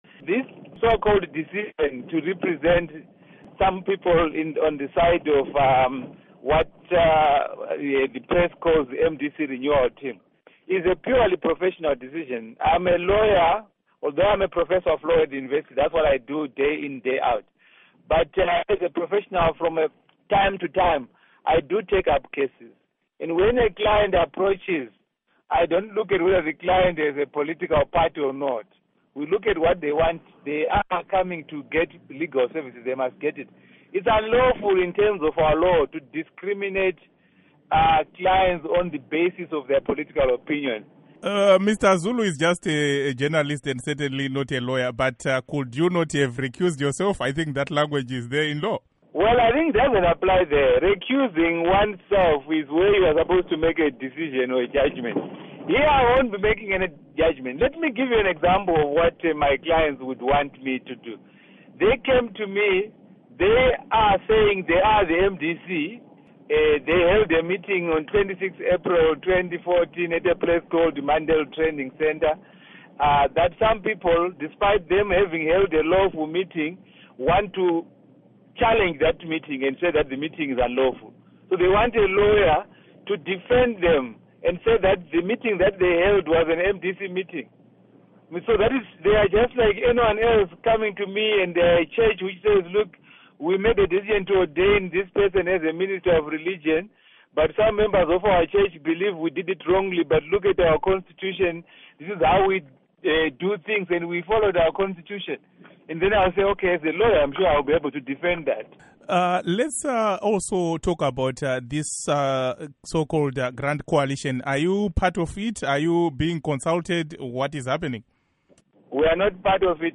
Interview With Lovemore Madhuku